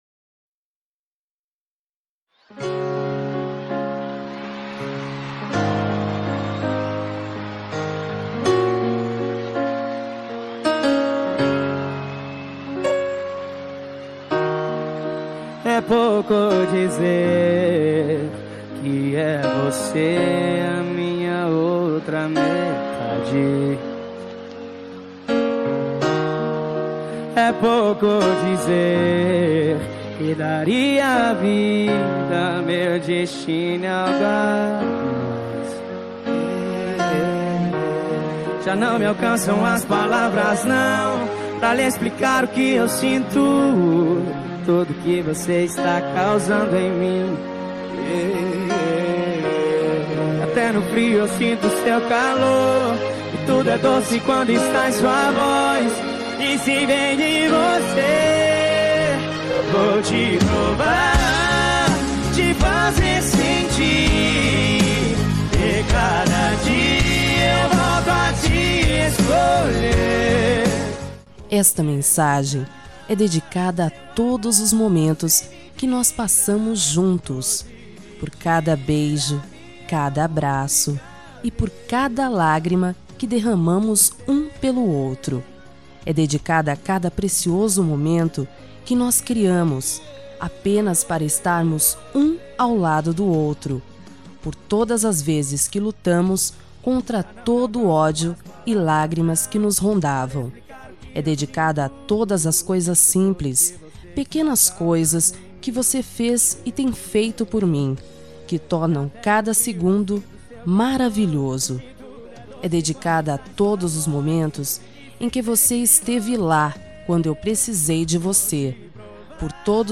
Telemensagem Romântica para Marido – Voz Feminina – Cód: 9065